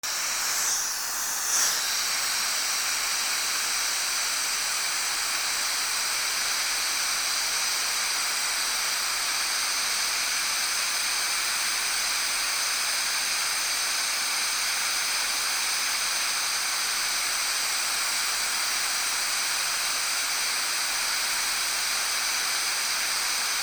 掃除機
吸引音 先頭少し乱れあり 『キュシュー』